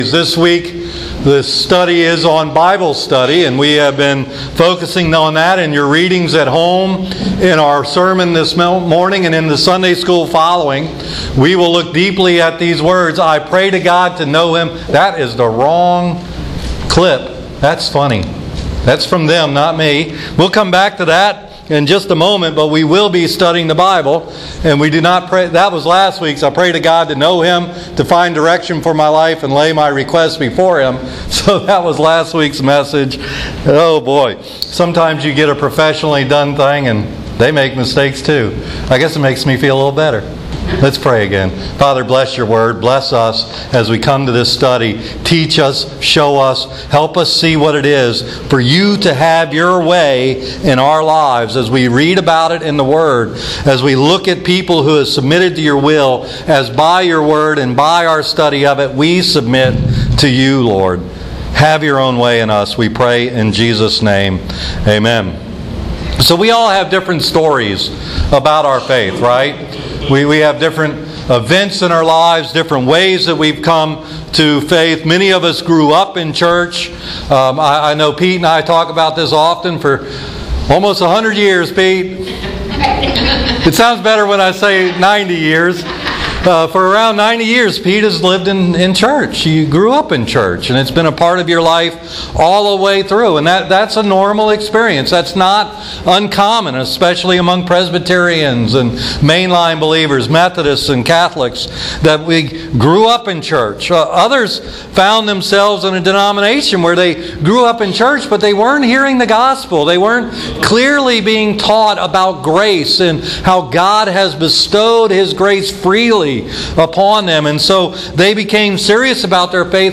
No that's not a mistake - we missed the week 12 study on prayer and moved on to Bible Study - maybe later this year. This sermon was well received by the hearers even though it was much longer than usual!